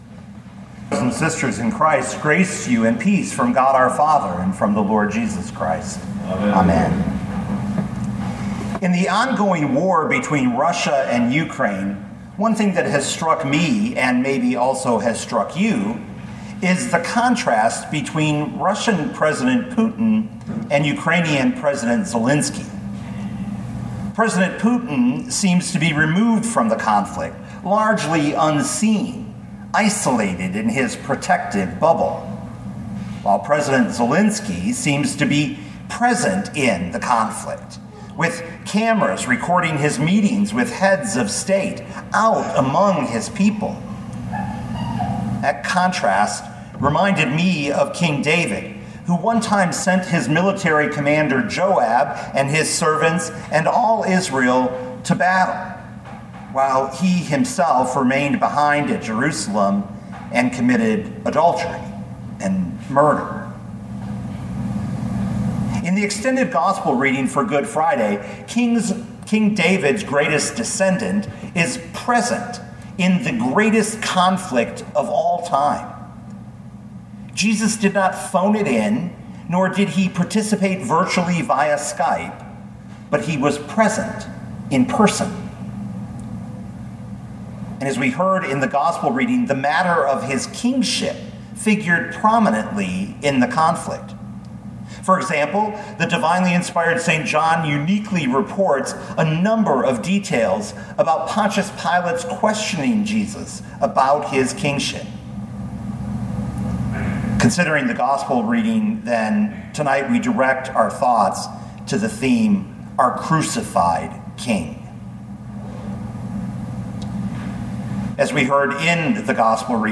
2022 John 18:1-19:42 Listen to the sermon with the player below, or, download the audio.